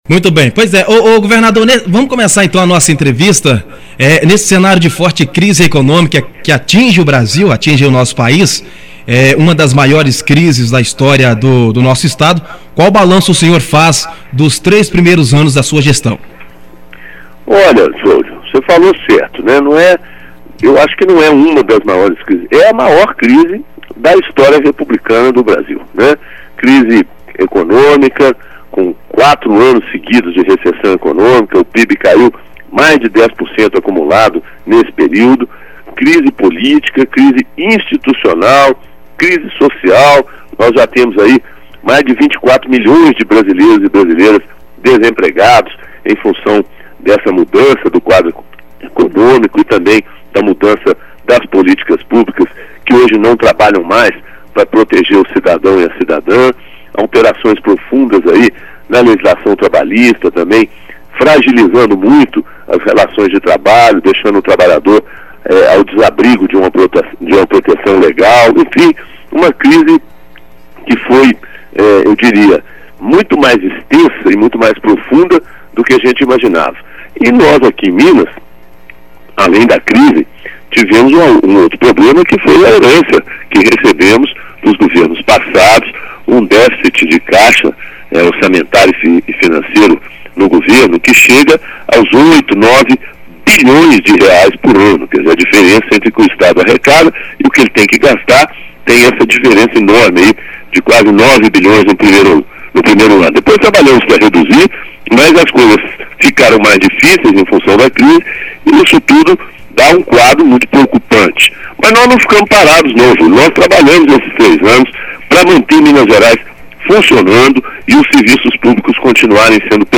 GOVERNADOR DE MG FERNANDO PIMENTEL CONCEDE ENTREVISTA EXCLUSIVA À RÁDIO MONTANHESA